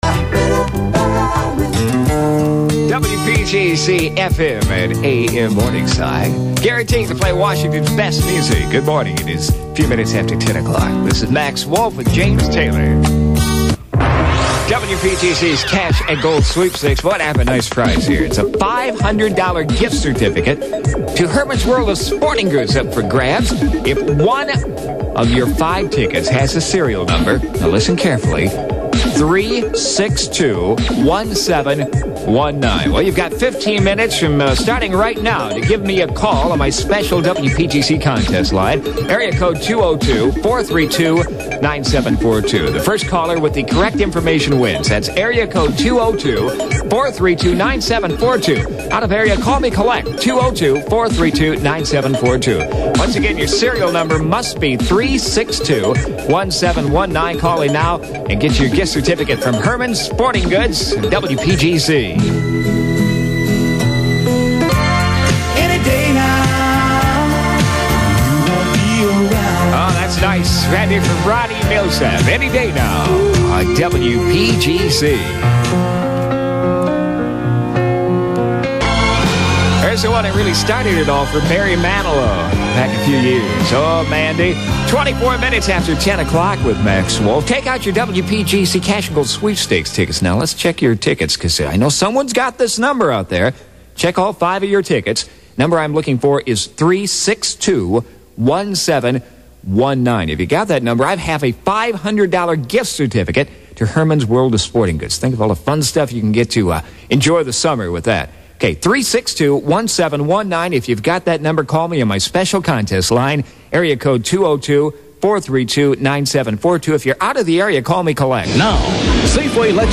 Winning numbers were read on the air.
All commercials heard on this tape are agency produced.
The station's legendary (((reverb))) was still part of the audio chain but had been trimmed way back, to the point of almost being inaudible.